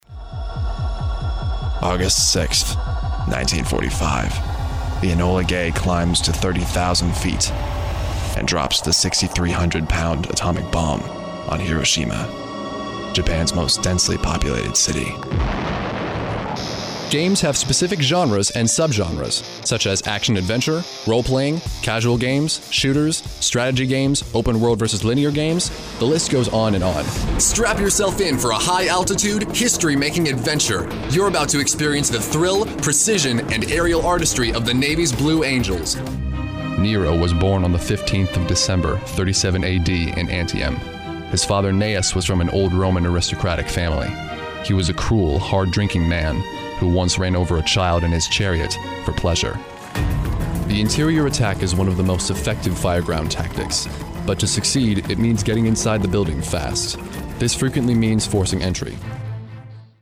Young and mature voices, inhuman and creature voices.
Location: Greenville, SC, USA Languages: english 123 Accents: standard us Voice Filters: VOICEOVER GENRE documentary gaming gaming promos